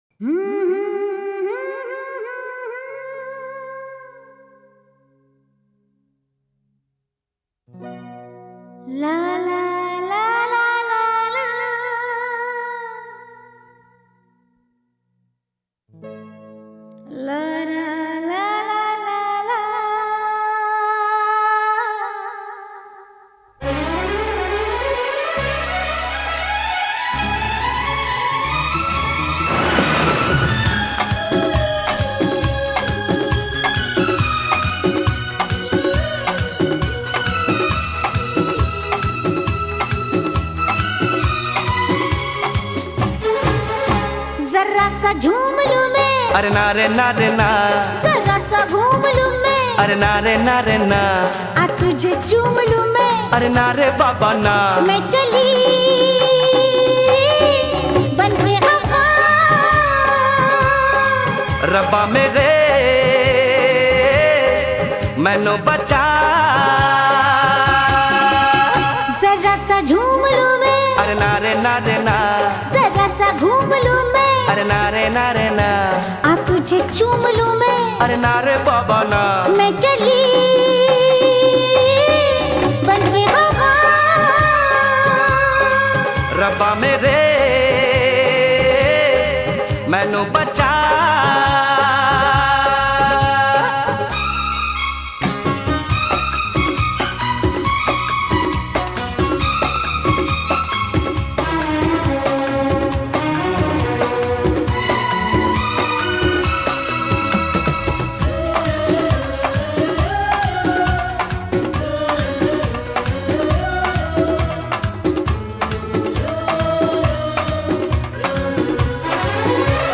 Carpeta: musica hindu mp3